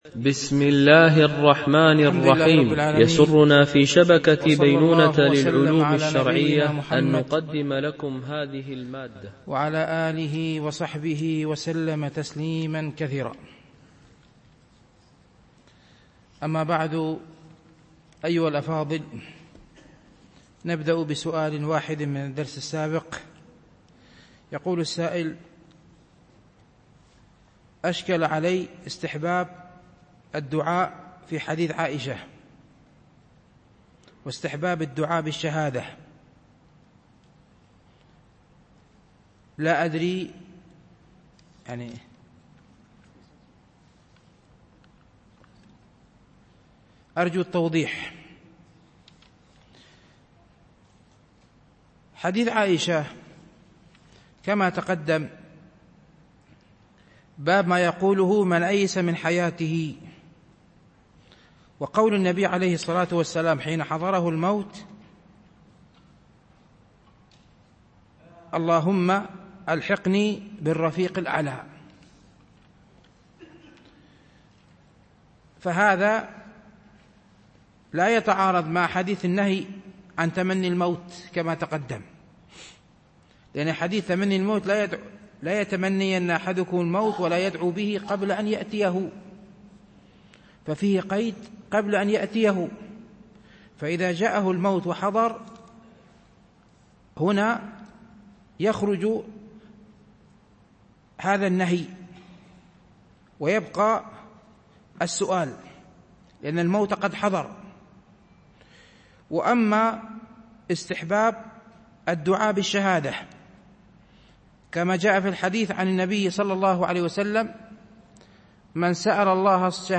، باب 150: تلقين المحتضر، الحديث 918 – 923 ) الألبوم: شبكة بينونة للعلوم الشرعية التتبع: 240 المدة: 45:19 دقائق (10.41 م.بايت) التنسيق: MP3 Mono 22kHz 32Kbps (CBR)